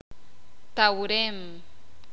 [ʔaχ.jak.’ɓa:l̥ ts͡aʔ] sustantivo Clown